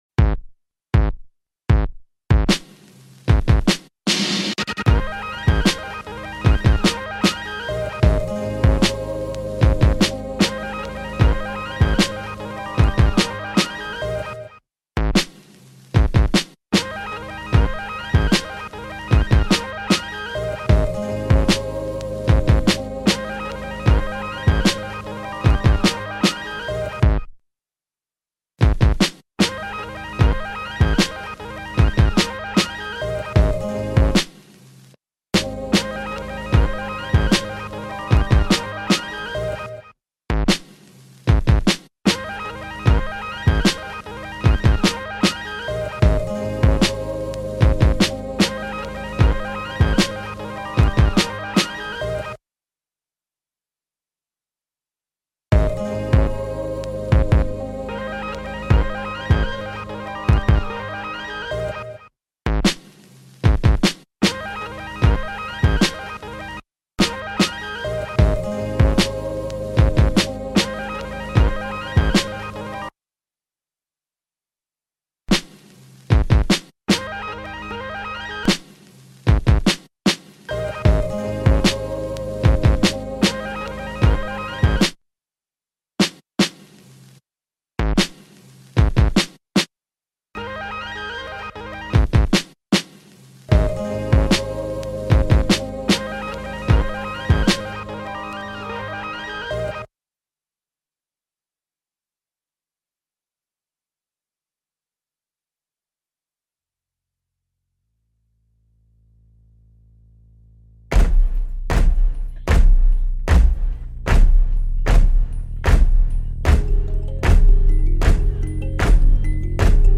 official instrumental
R&B Instrumental